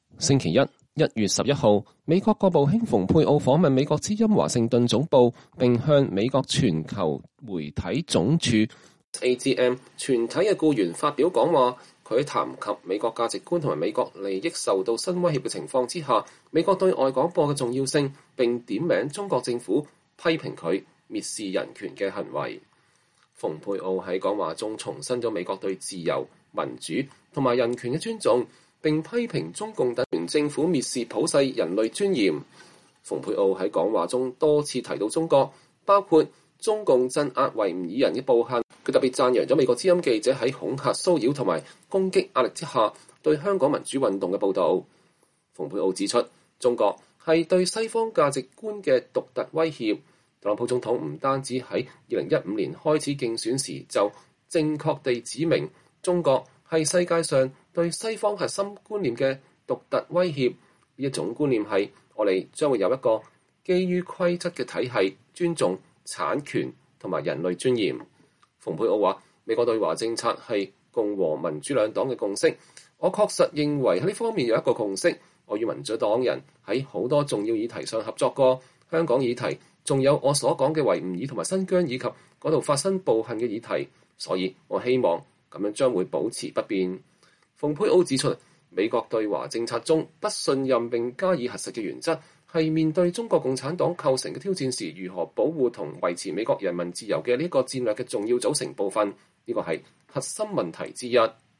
國務卿蓬佩奧在美國之音總部發表講話(2021年1月11日)